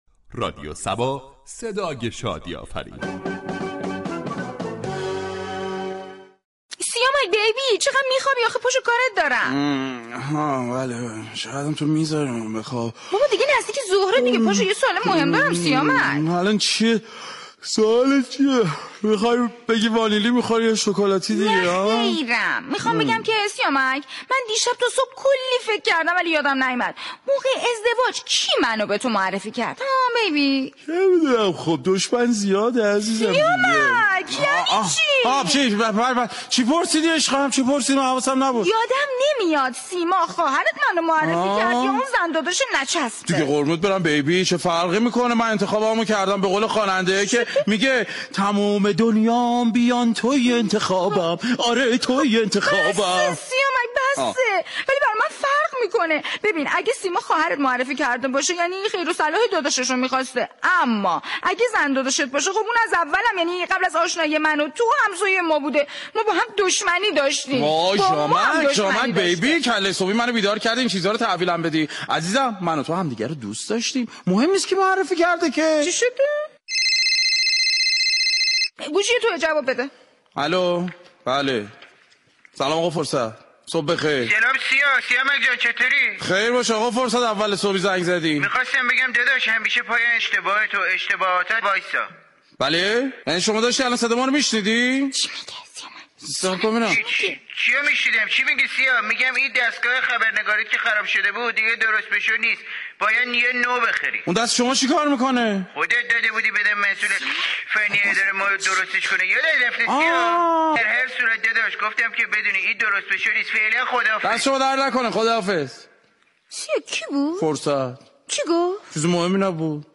شهر فرنگ در بخش نمایشی با بیان طنز به موضوع "ملاك های انتخاب همسر "پرداخته است ،در ادامه شنونده این بخش باشید.